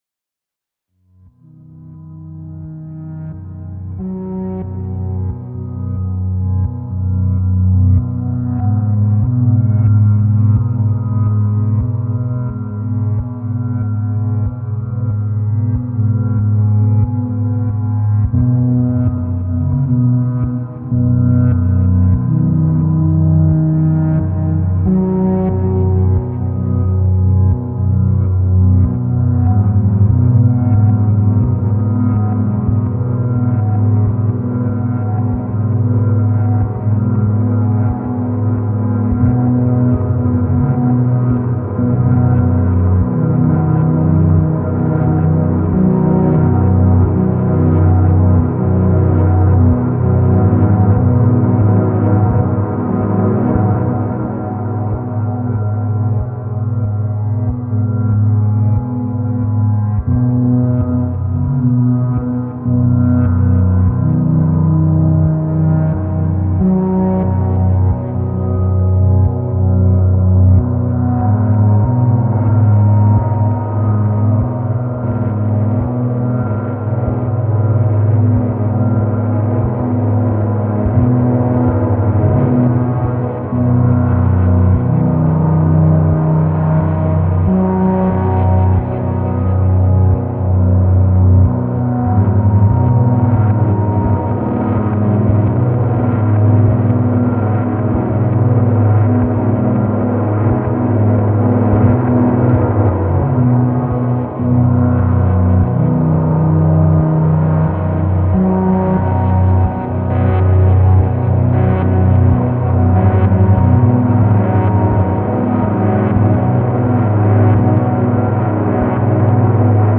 Little guitar snippet caught on the Blooper and then run back through a VST amp with the space echo on a send… Gets loud and starts clipping a tape plugin in Ableton, but you get the point.